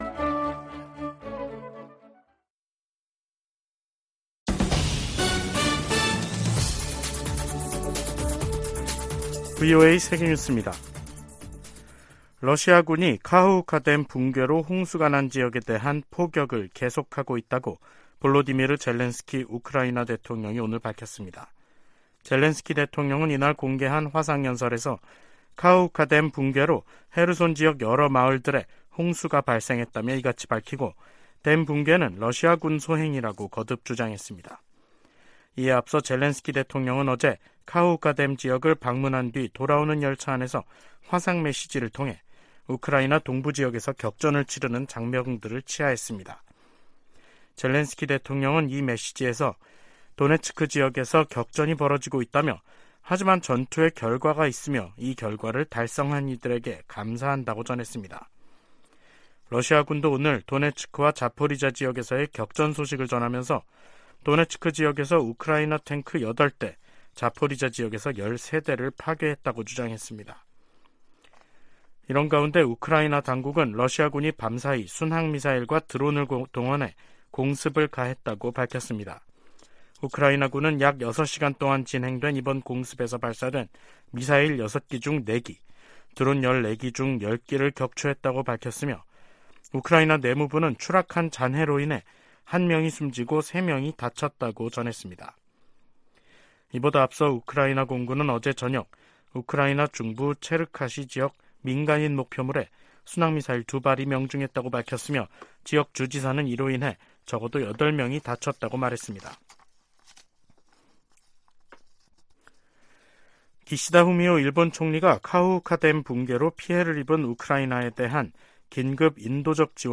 생방송 여기는 워싱턴입니다 2023/6/9 저녁
세계 뉴스와 함께 미국의 모든 것을 소개하는 '생방송 여기는 워싱턴입니다', 2023년 6월 9일 저녁 방송입니다. '지구촌 오늘'에서는 우크라이나군이 서방 지원 탱크 등을 전장에 투입해 '대반격'을 진행하는 소식 전해드리고, '아메리카 나우'에서는 도널드 트럼프 전 대통령이 기밀문서 유출 사건으로 기소된 이야기 살펴보겠습니다.